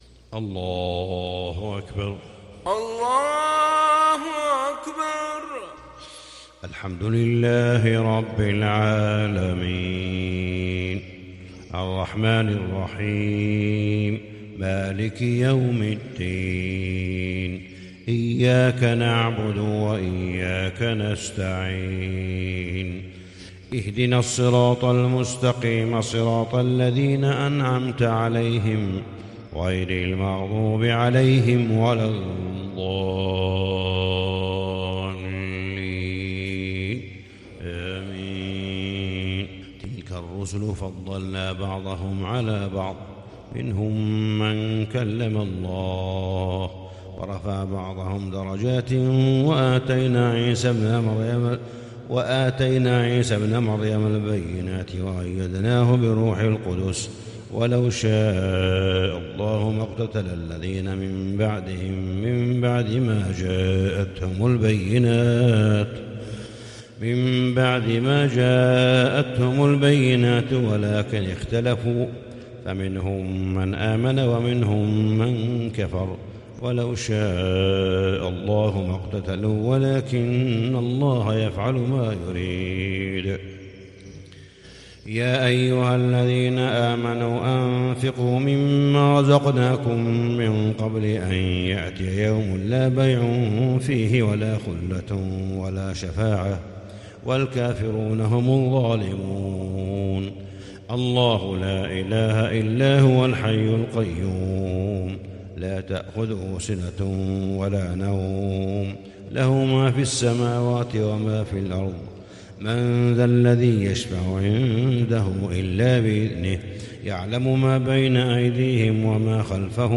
صلاة الفجر للشيخ صالح بن حميد 22 جمادي الأول 1441 هـ
تِلَاوَات الْحَرَمَيْن .